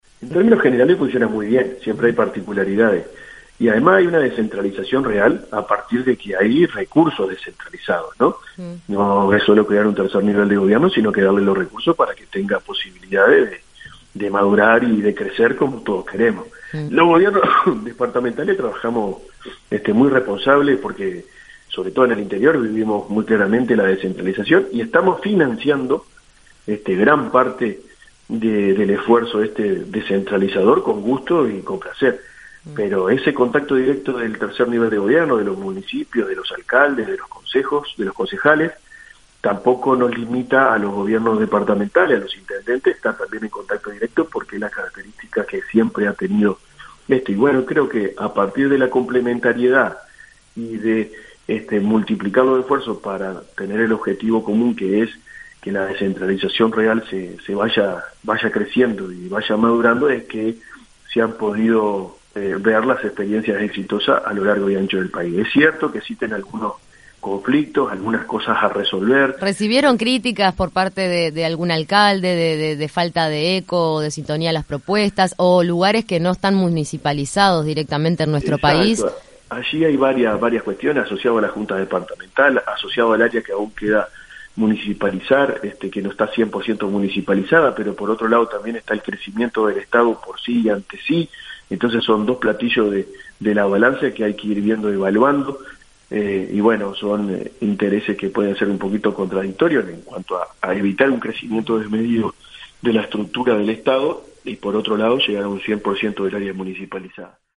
En 970 Noticias – Primera Edición, recibimos al intendente de Florida y presidente del Congreso de Intendentes, Guillermo López, quien además de destacar los avances en el último encuentro entre pares, reconoció que existen problemas entre las intendencias y los municipios.